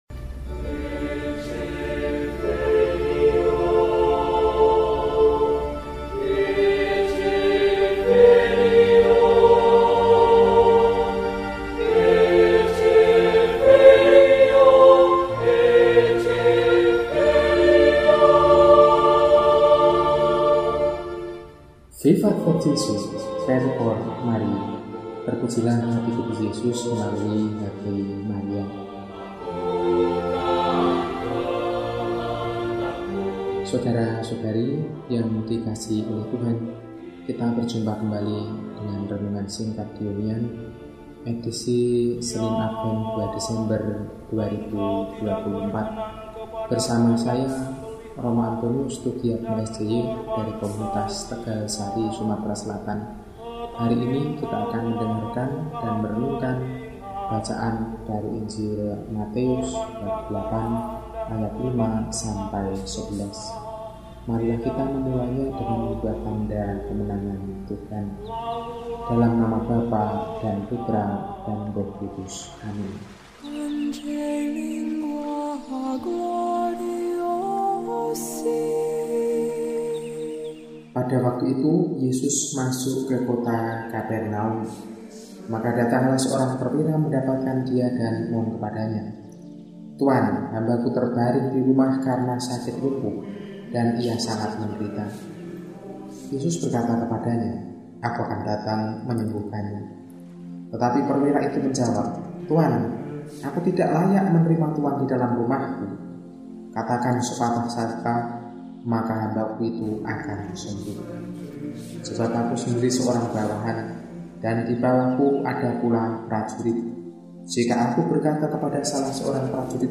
Senin, 02 Desember 2024 – Hari Biasa Pekan I Adven – RESI (Renungan Singkat) DEHONIAN